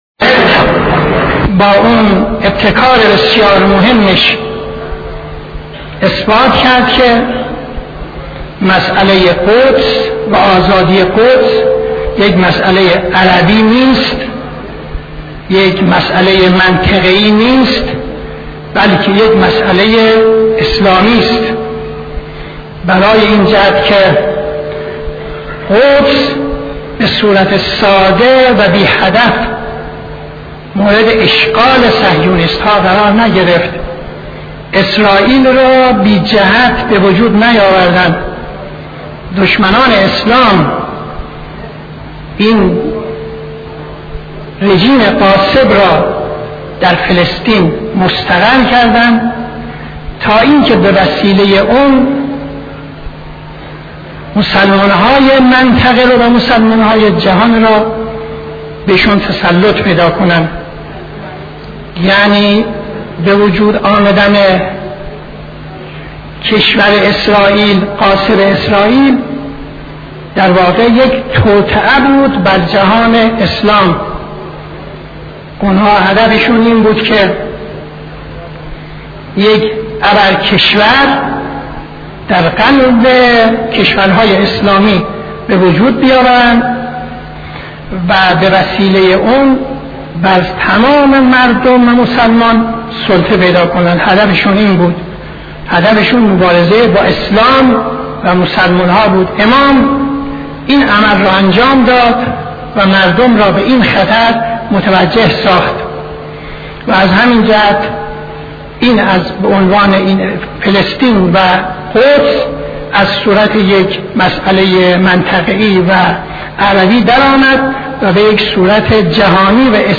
خطبه دوم نماز جمعه 05-12-73